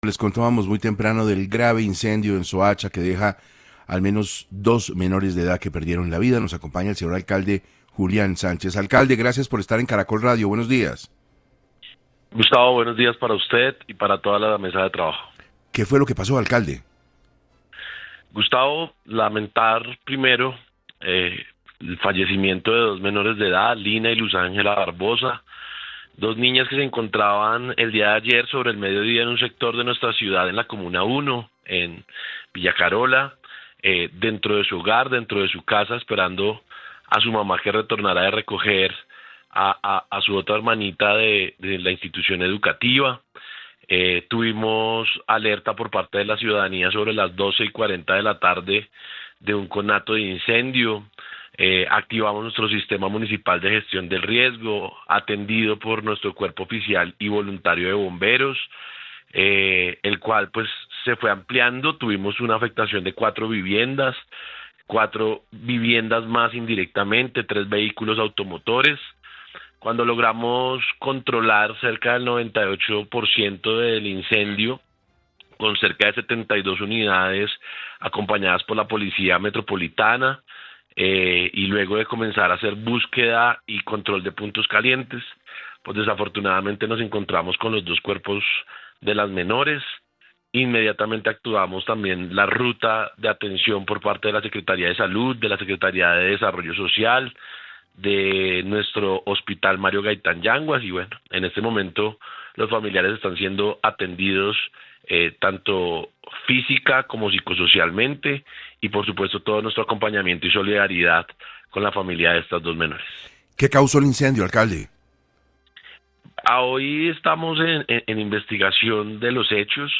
El alcalde Julián Sánchez explicó en 6AM cómo avanza la investigación para determinar el origen de los hechos.
En la entrevista, el Alcalde advirtió que, debido a la situación social de la localidad, el incendio podría tratarse de un acto criminal.